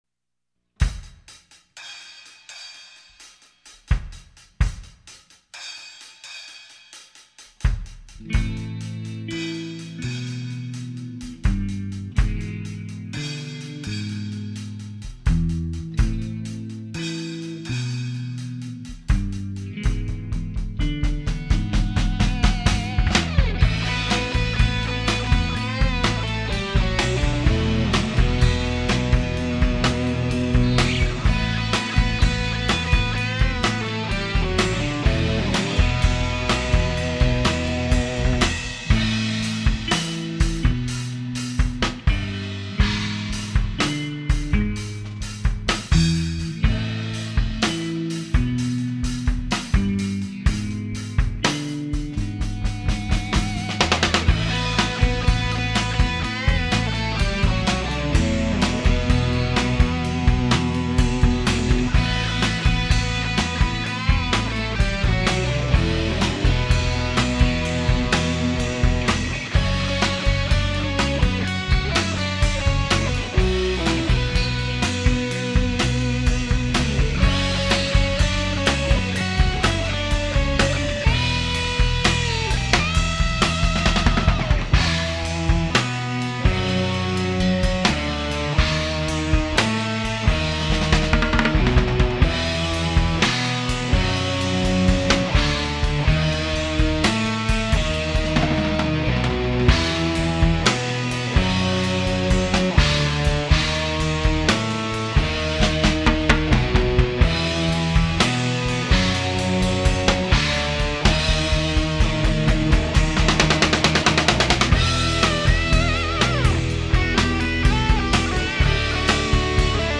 Santa Cruz home studio recordings  (1999)
3 guitars, bass and drums